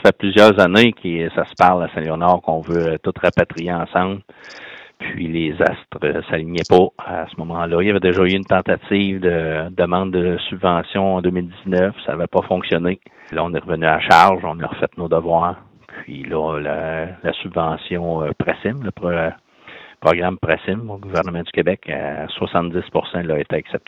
Le maire, Laurent Marcotte, a mentionné qu’il a fallu plusieurs années pour voir le garage et la caserne sous le même toit.